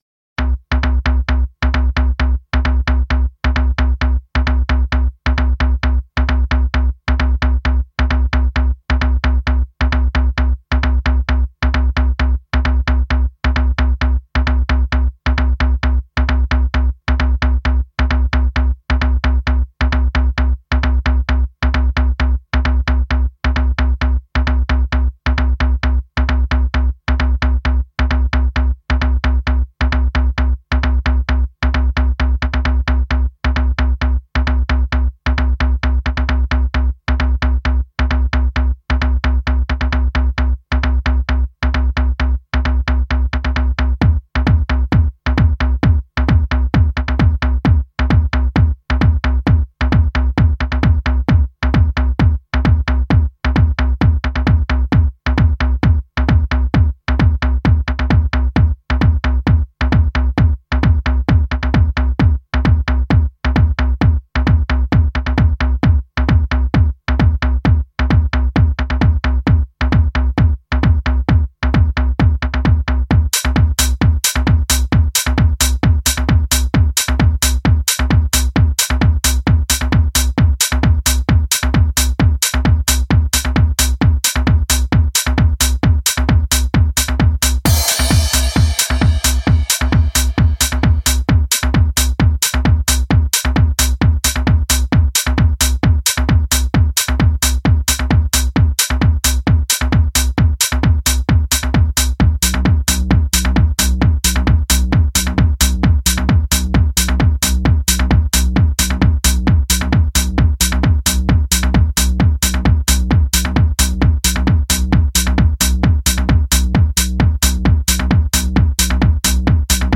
Жанр: House